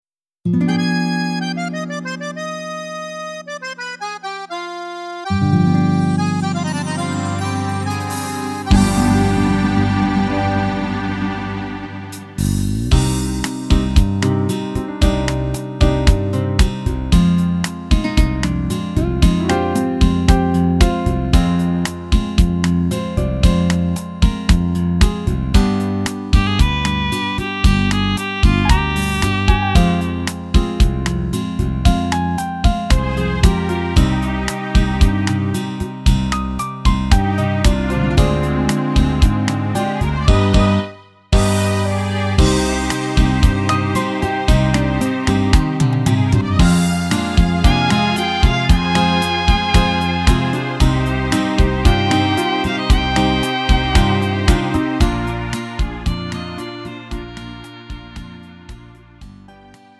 음정 원키 3:57
장르 구분 Lite MR